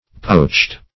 poached - definition of poached - synonyms, pronunciation, spelling from Free Dictionary
Poach \Poach\ (p[=o]ch), v. t. [imp. & p. p. Poached